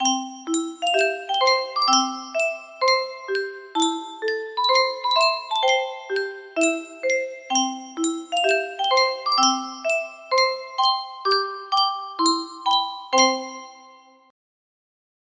Yunsheng Music Box - Battle Hymn of the Republic Y927 music box melody
Full range 60